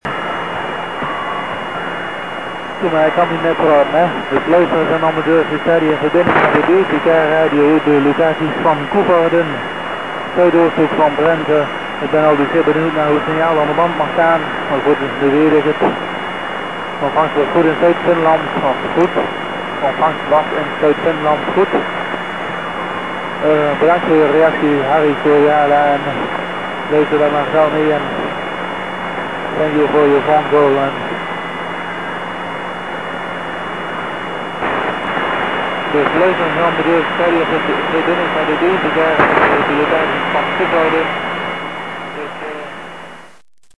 Mp3-clips of pirate radio stations which I have heard here:          / *= Heard in Paimio with 800 mtr L-wire
here are greetings to me!